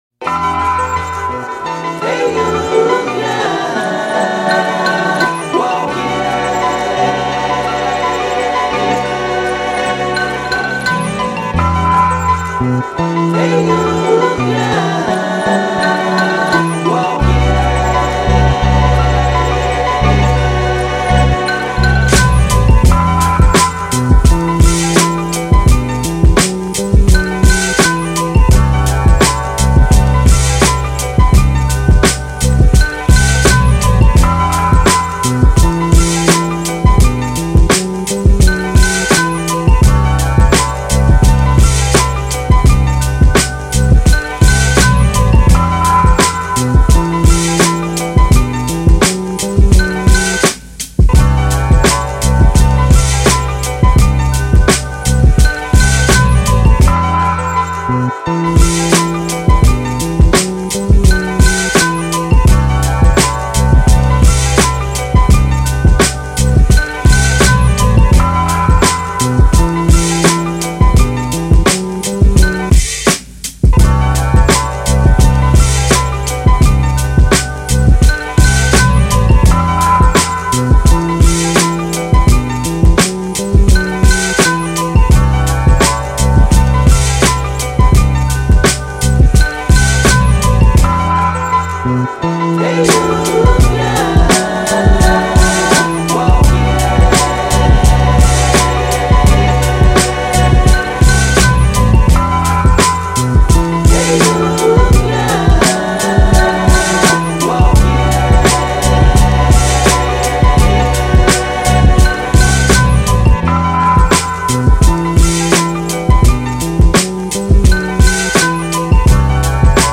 Boom Bap Instrumental